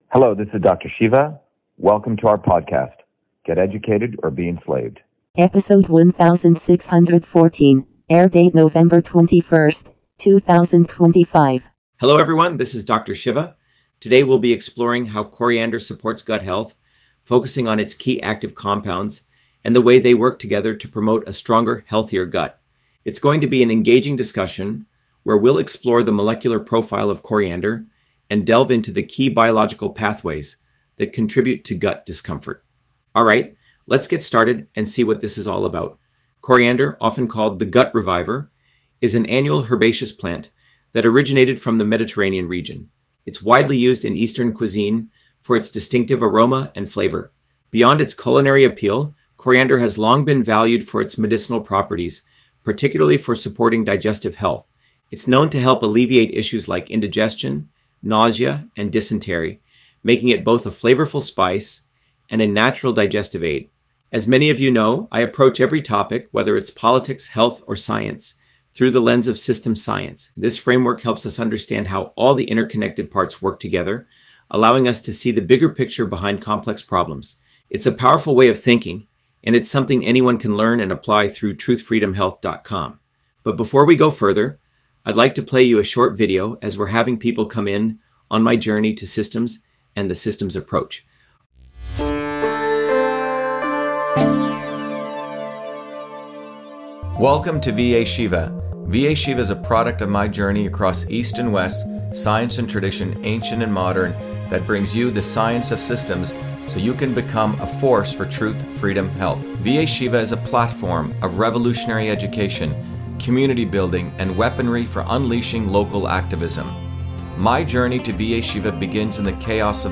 In this interview, Dr.SHIVA Ayyadurai, MIT PhD, Inventor of Email, Scientist, Engineer and Candidate for President, Talks about Coriander on Gut Health: A Whole Systems Approach